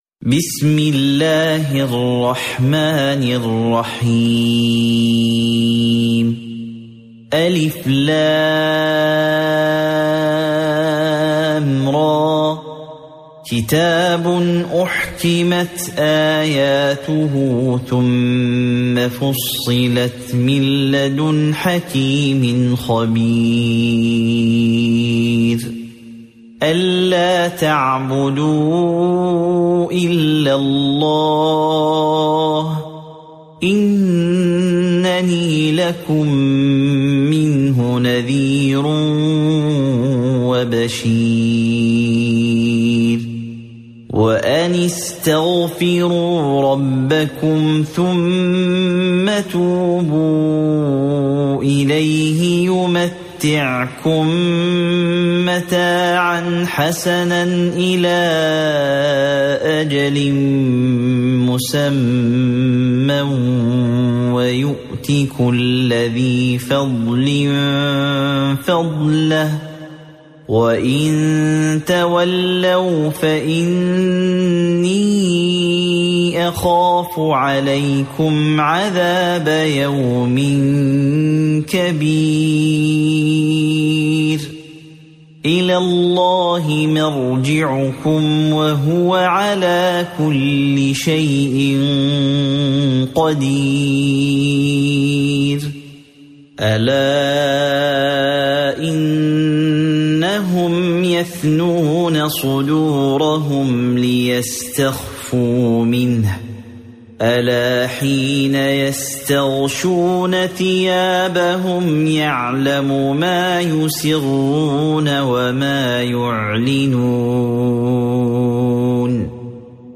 سورة هود مكية عدد الآيات:123 مكتوبة بخط عثماني كبير واضح من المصحف الشريف مع التفسير والتلاوة بصوت مشاهير القراء من موقع القرآن الكريم إسلام أون لاين